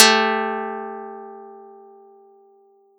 Audacity_pluck_11_13.wav